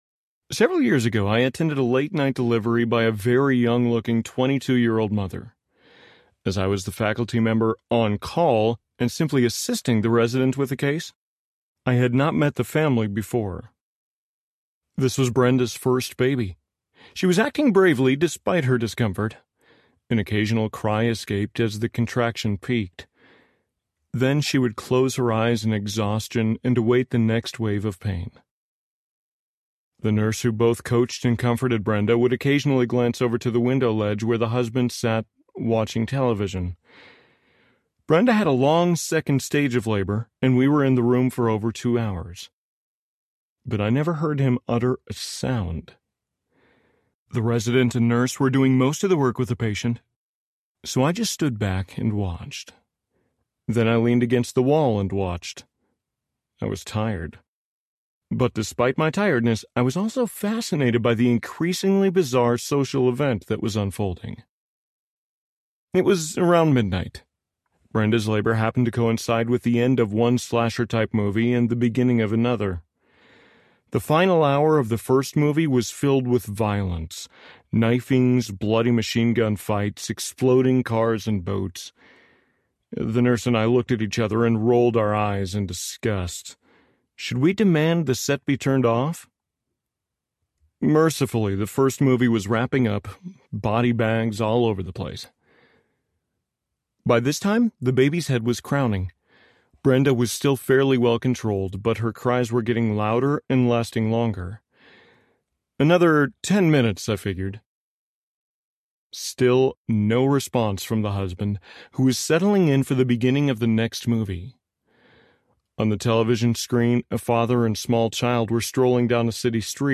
Margin Audiobook
Narrator
7.4 Hrs. – Unabridged